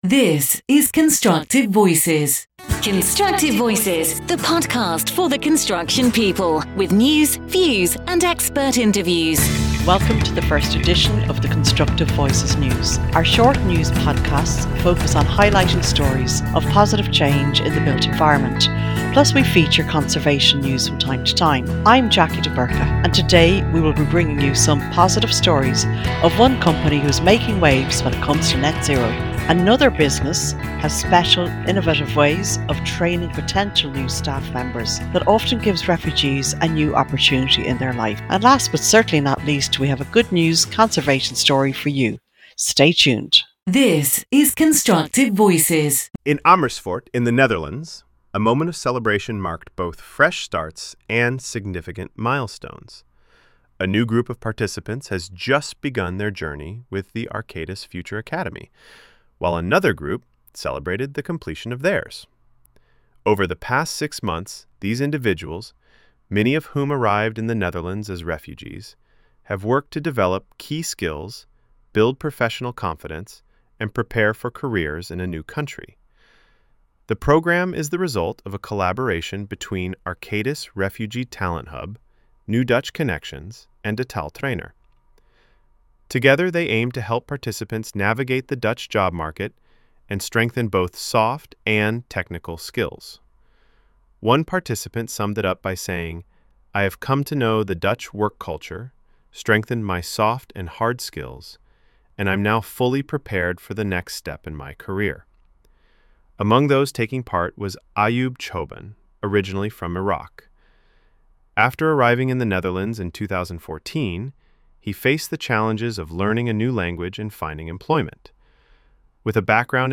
Welcome to the very first edition of the Constructive Voices News — a short, sharp burst of uplifting stories from the built environment, with a side of conservation.